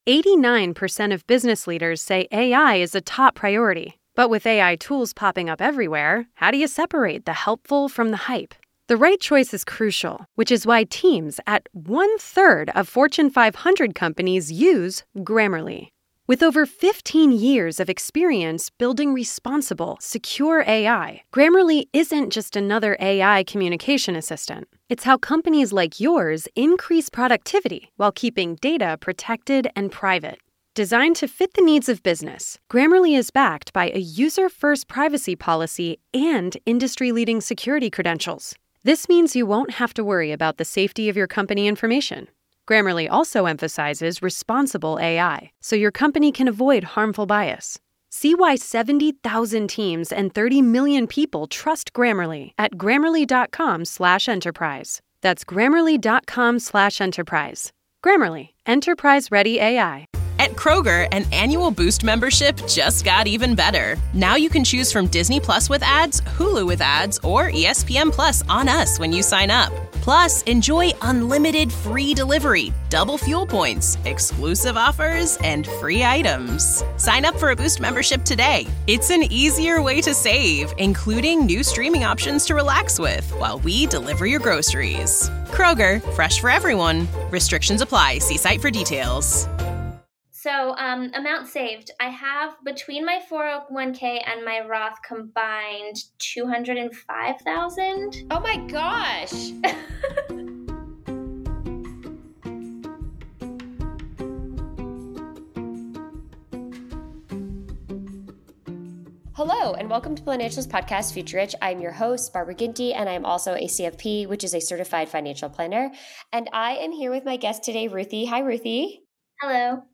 Future Rich Podcast aims to change that! We are a female-hosted and female-focused podcast that covers all things money -- how it is spent, saved, and everything in between!